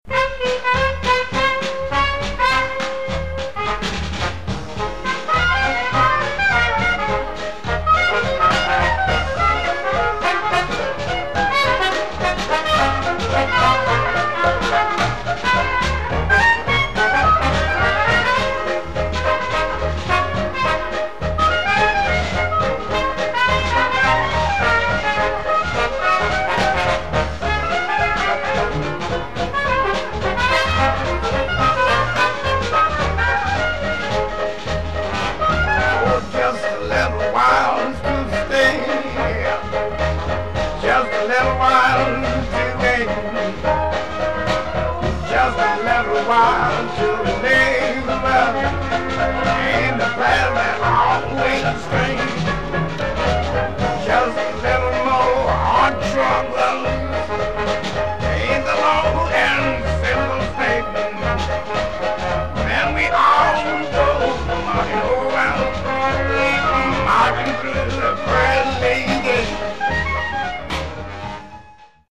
at Zion Hill Church